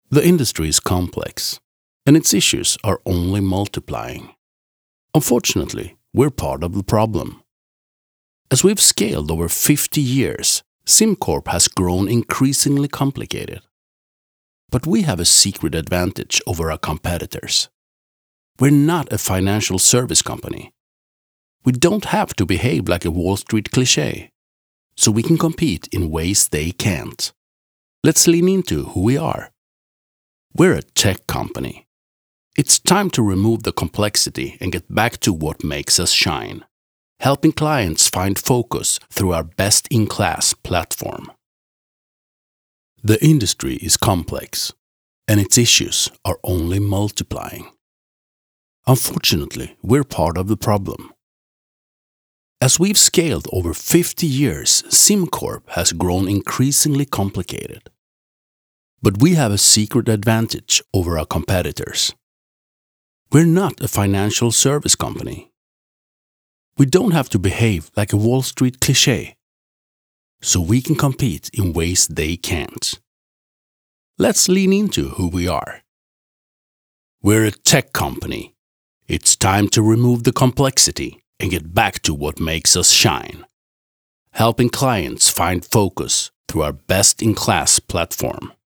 Male
Confident, Warm, Versatile
Microphone: AKG c414b-uls, Shure SM7